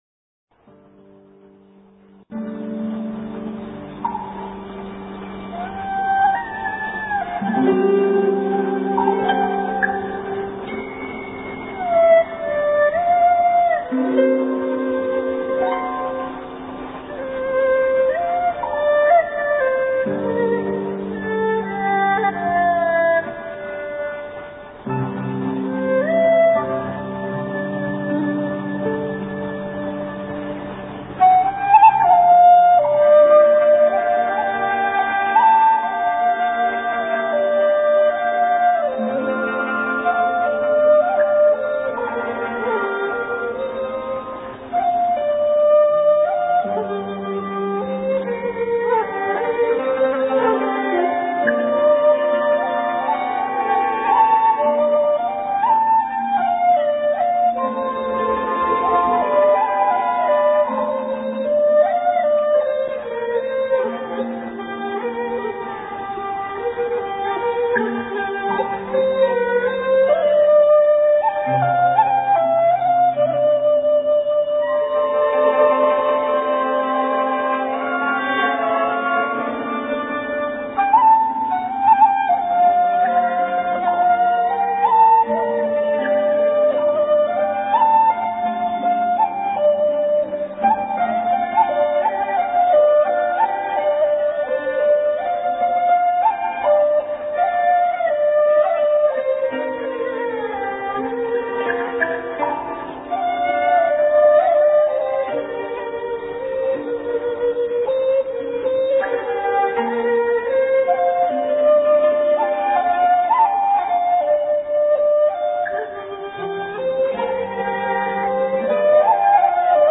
笛子
樂曲共分三段：第一段如泣如訴，怨恨連綿；第二段表達了思念更深，愁緒萬千的深切感情；第三段爲慢起漸快的流水板，情緒明快激奮，充滿對美好生活的渴望。
這是廣東音樂中最早的分段體式作品，並且採用了變調的手法，尾聲爲快板，反複二至三次，由慢趨快到高潮結束。